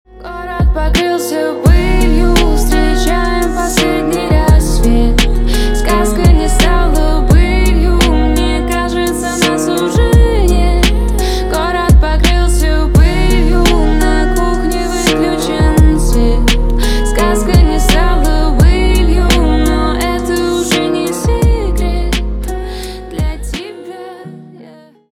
на русском грустные